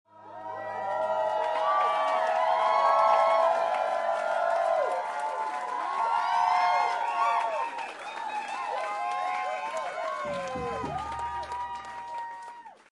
Download Cheering sound effect for free.
Cheering